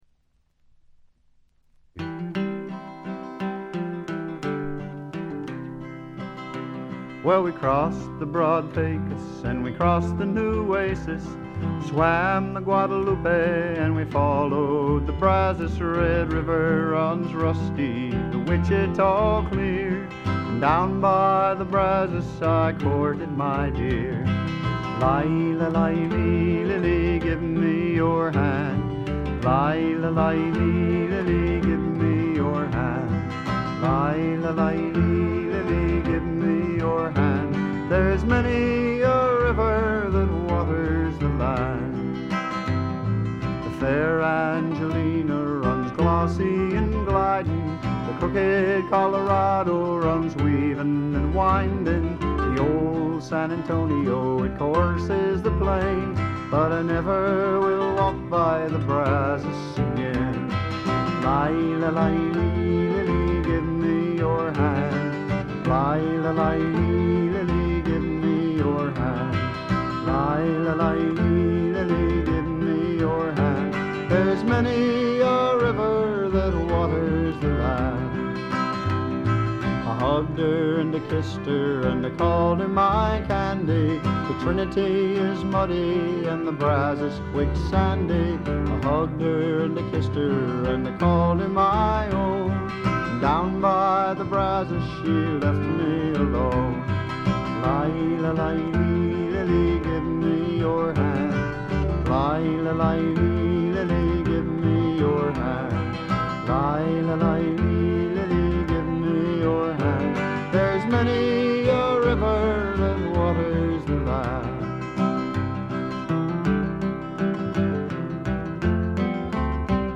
ほとんどノイズ感無し。
内容的にも前作路線で弾き語りに近いシンプルなバックのみで歌われる全14曲。
ロンサムで暖かい空気が部屋の中で静かに満ちていくような感覚がたまらないです。
試聴曲は現品からの取り込み音源です。